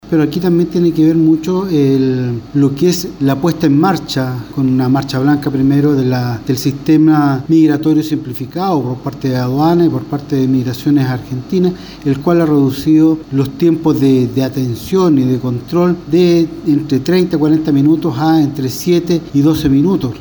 Ante ello, el delegado Presidencial Provincial de Los Andes, Cristian Aravena, señaló que se han reducido los tiempos de atención de entre 40 o 30 a minutos a 12 o 7 minutos.
cu-libertadores-dpp-cristian-aravena.mp3